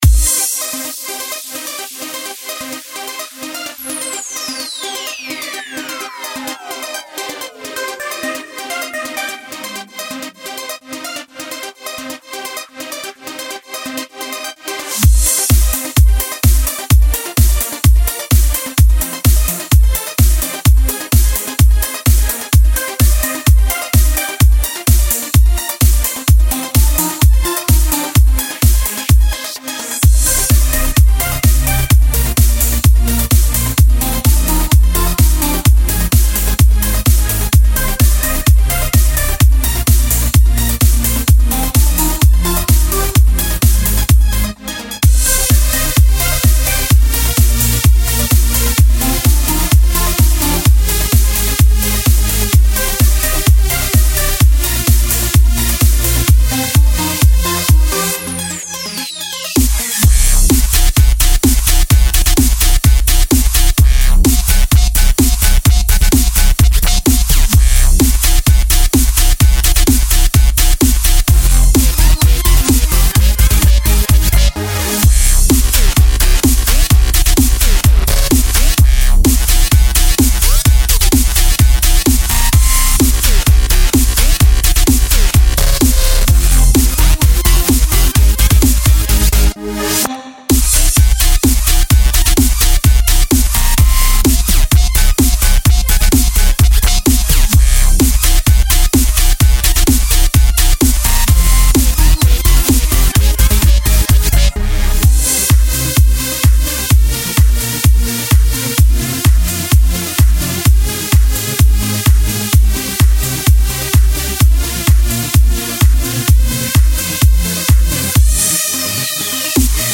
Žánr: Electro/Dance
Genres: Dubstep, Music, Electronic, Dance